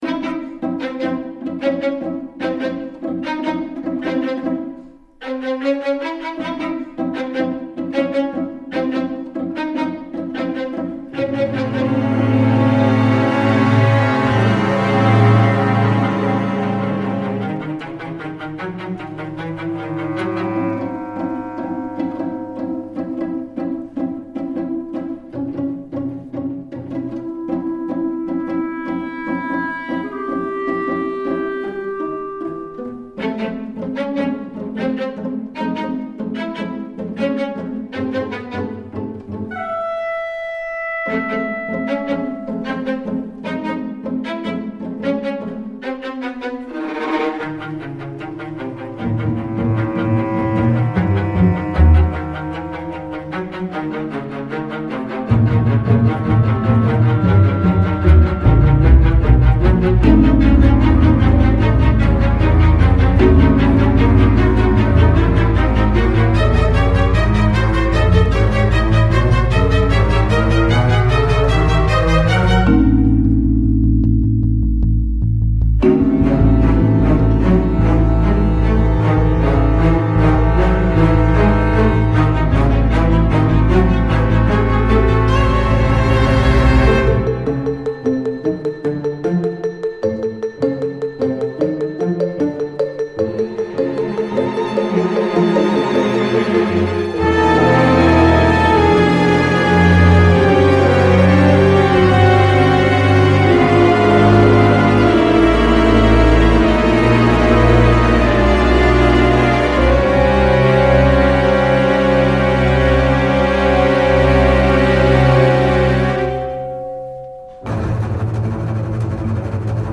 Une musique de thriller
GuestGuest Pas mal de cordes pour illustrer ce braquage.